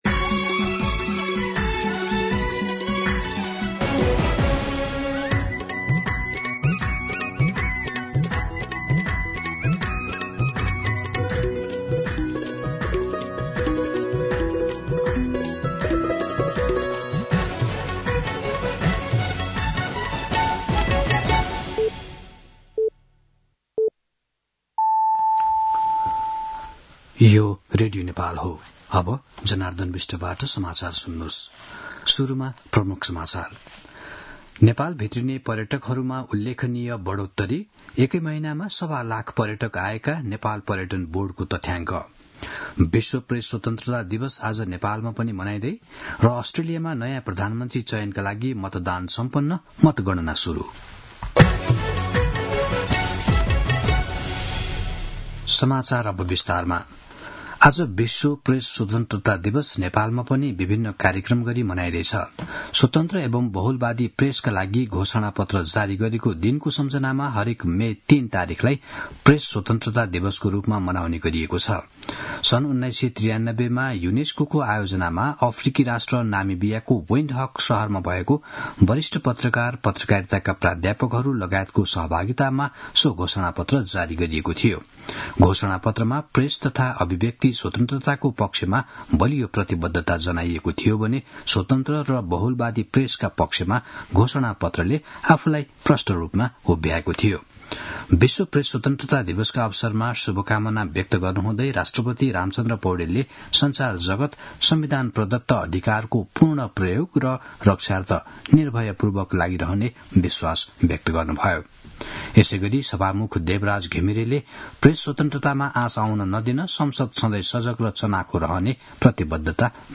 दिउँसो ३ बजेको नेपाली समाचार : २० वैशाख , २०८२
3pm-News-01-20.mp3